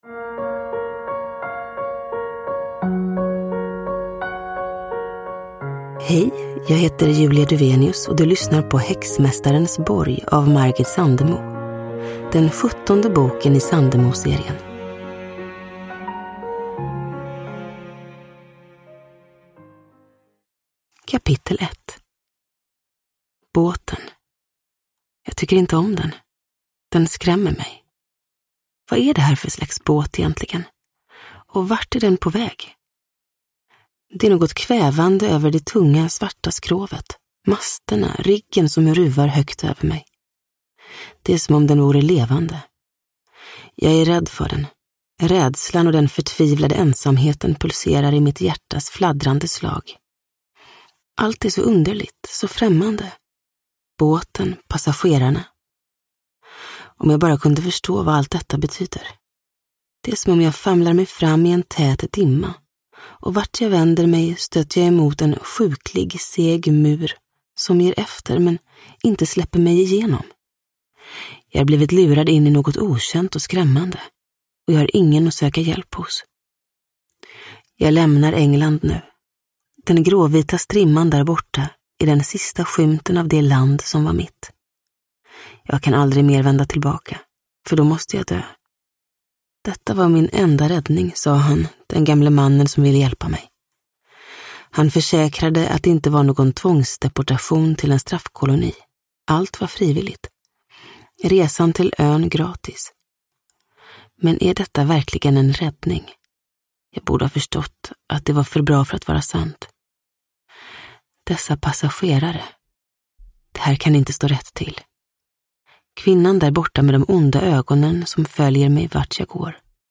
Häxmästarens borg – Ljudbok – Laddas ner
Sandemoserien är en unik samling fristående romaner av Margit Sandemo, inlästa av några av våra starkaste kvinnliga röster.